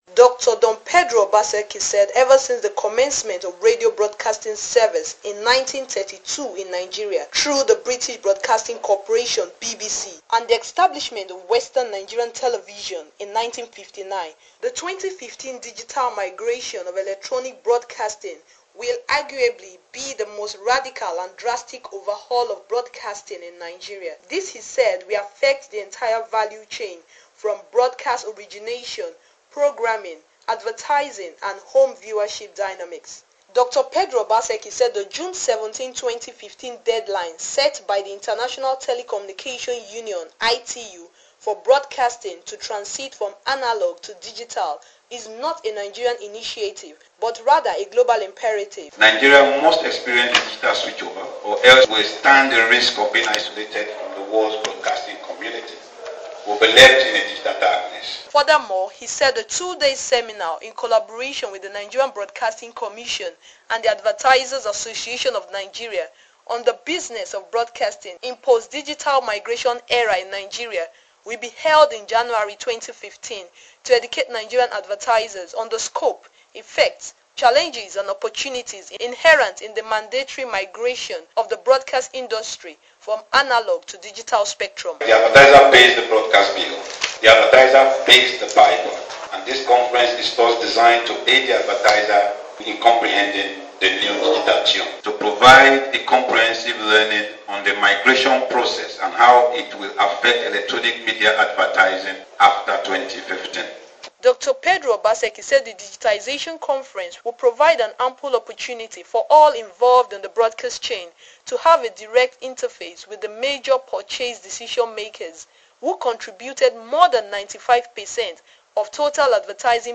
stated this at a media parley held in Lagos.